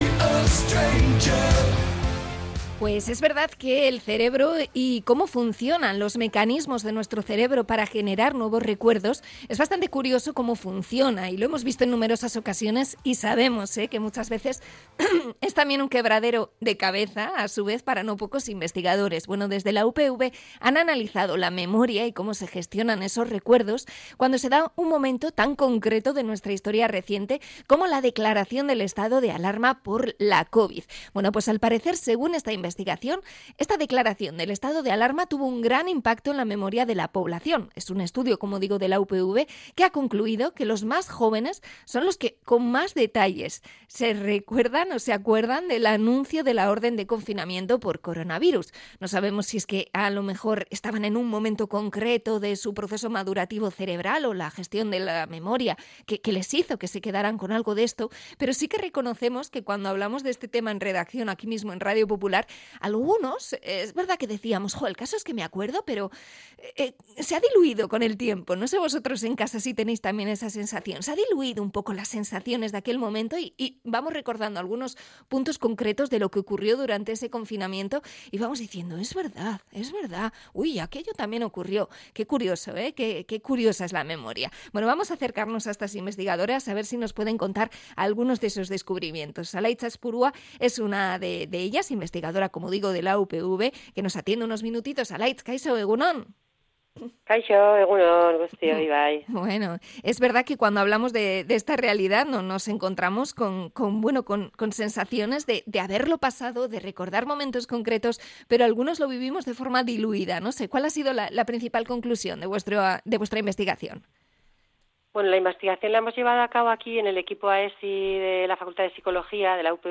Entrevista a investigadoras de la UPV sobre la memoria y el COVID